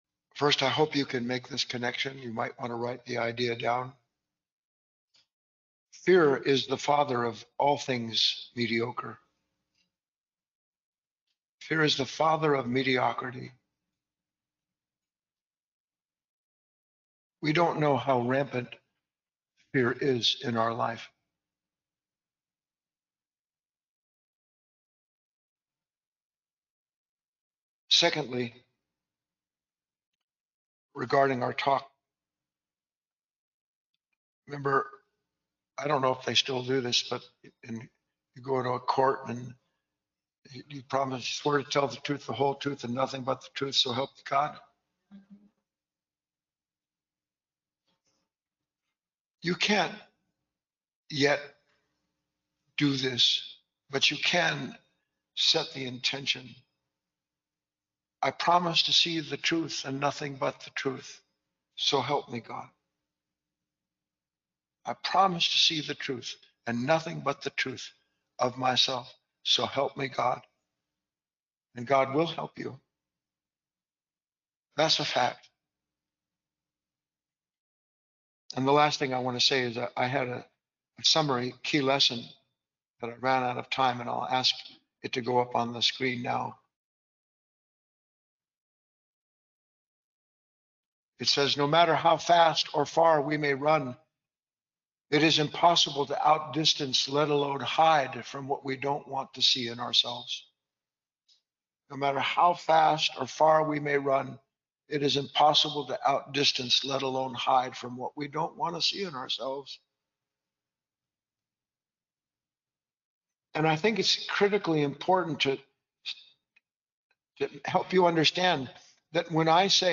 In this short talk